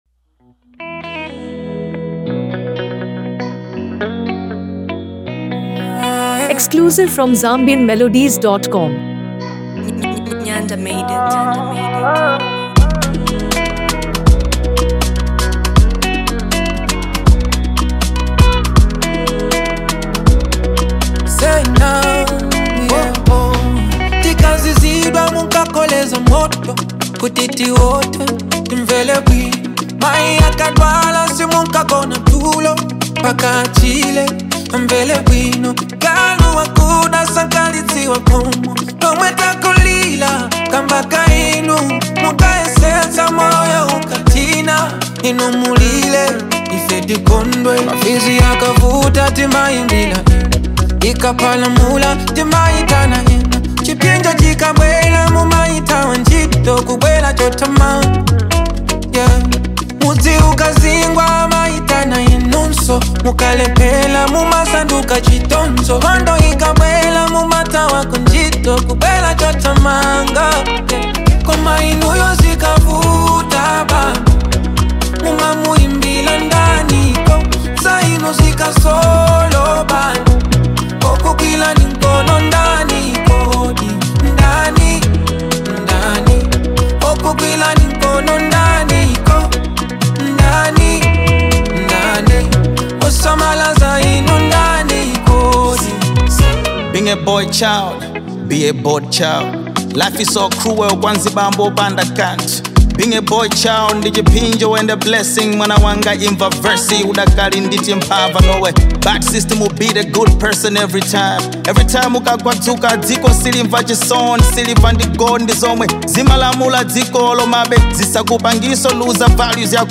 a perfect mix of soulful vocals and conscious rap